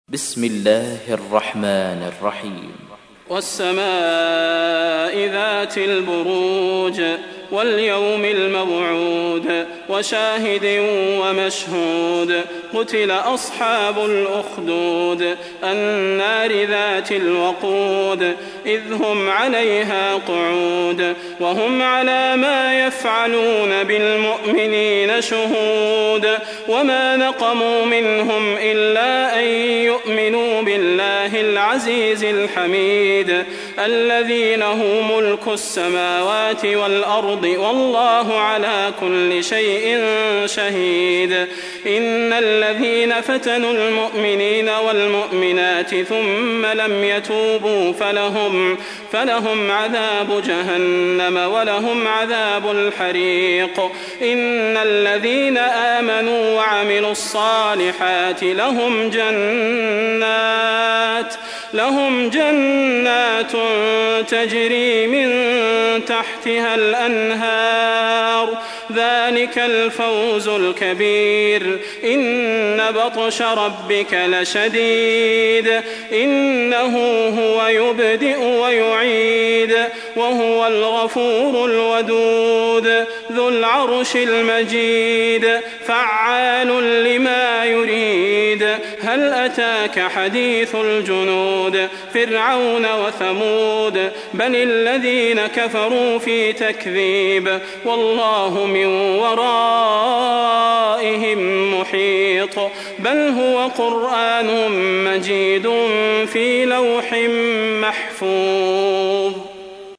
تحميل : 85. سورة البروج / القارئ صلاح البدير / القرآن الكريم / موقع يا حسين